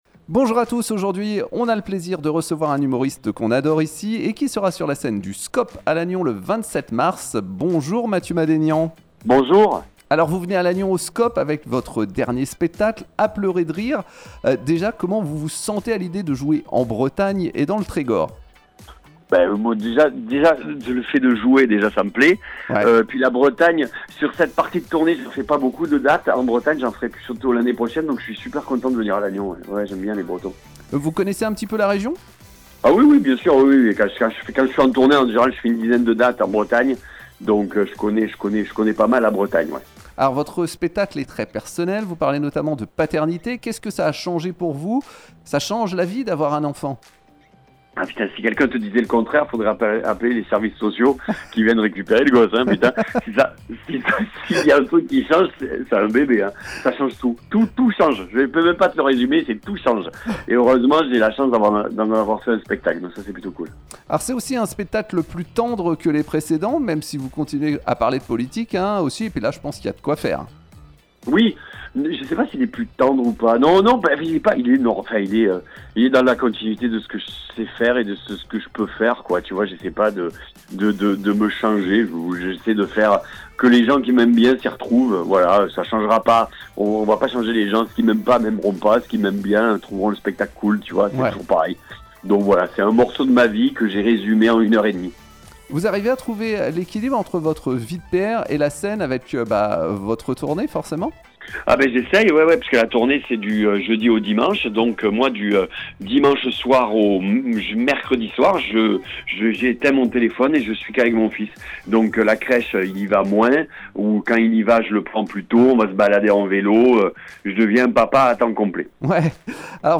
Mathieu Madénian – Interview et réactions